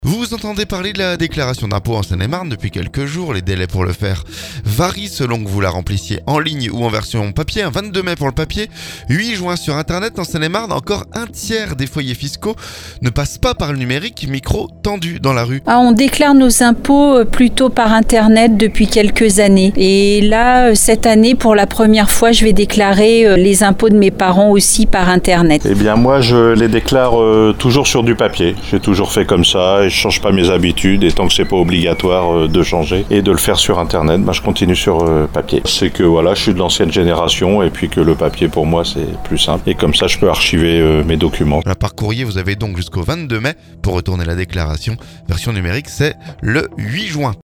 En Seine-et-Marne, encore un tiers des foyers fiscaux ne passe pas par le numérique... Micro tendu dans la rue.